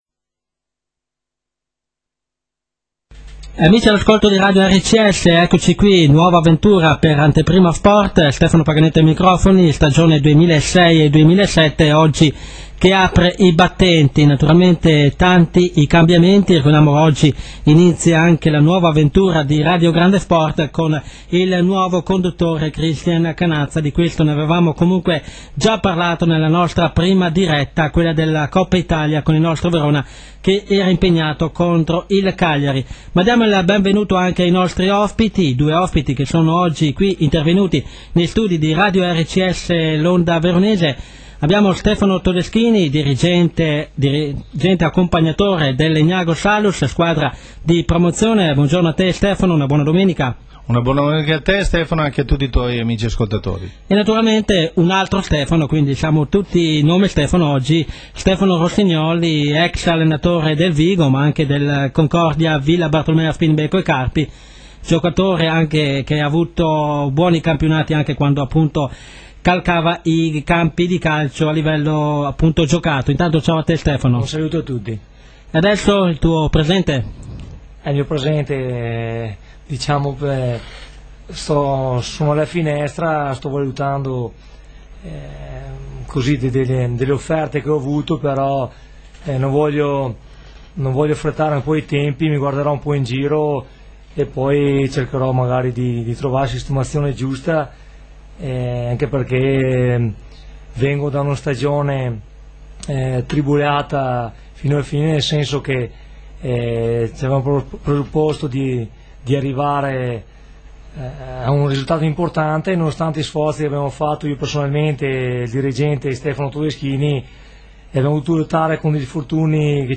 Intervista a Radio Grande Sport ( 3 Settembre 2006 )
Intervista Anteprima Sport 3 Settembre 2006.wma